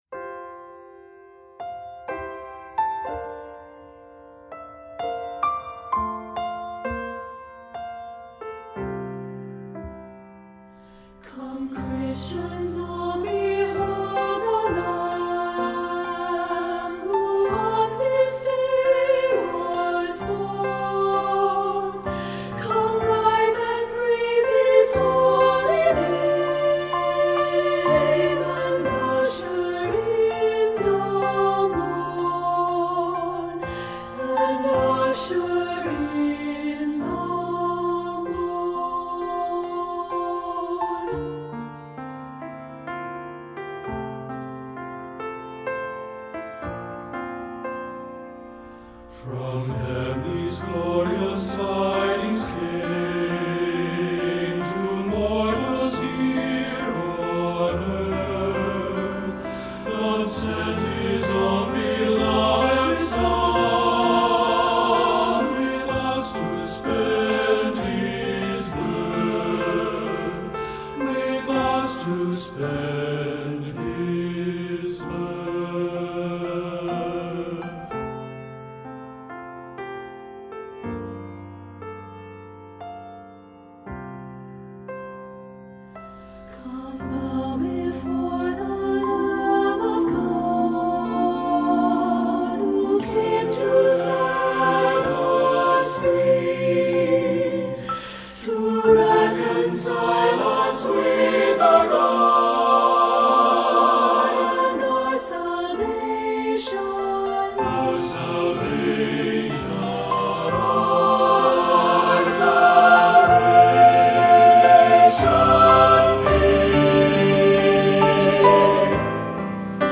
SATB with piano